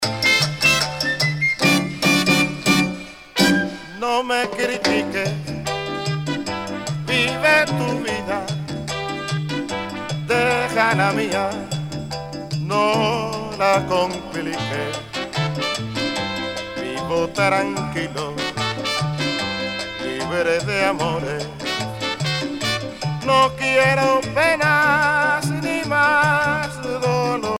cha cha cha
Pièce musicale éditée